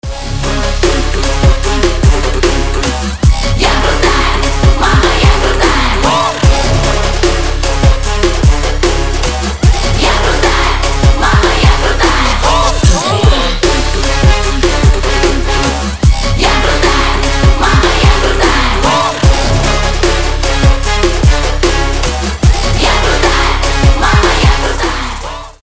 • Качество: 320, Stereo
мощные
Trap
женский рэп
Bass